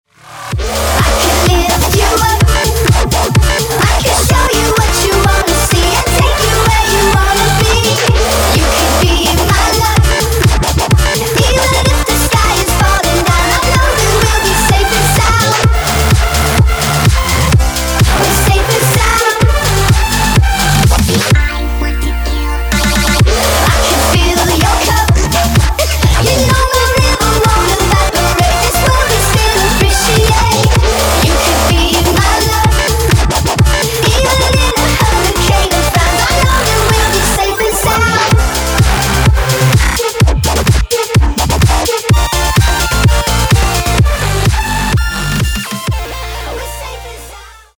club музыка